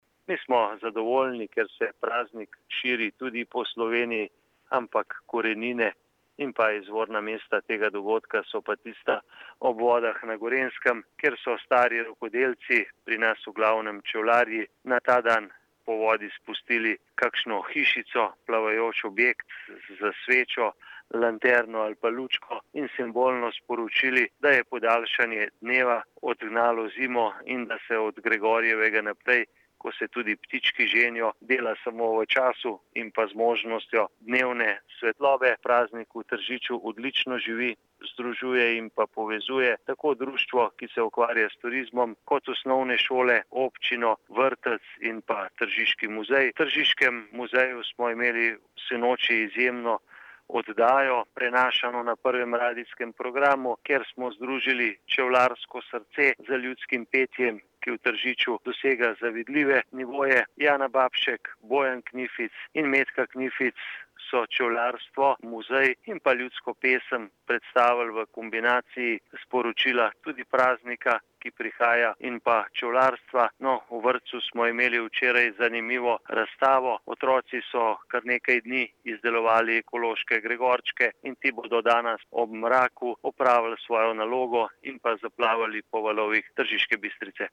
37424_izjavazupanobcinetrzicmag.borutsajovicoobicajuvucuvodo.mp3